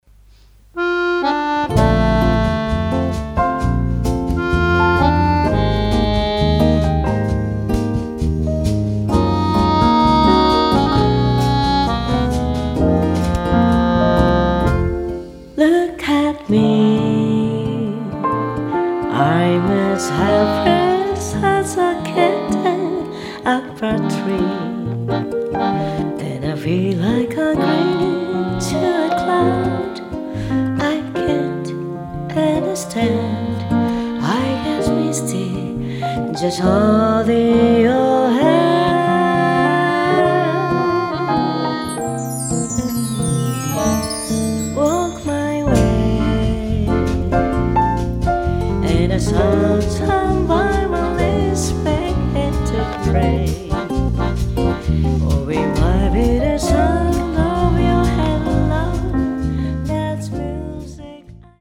ボーカリスト担当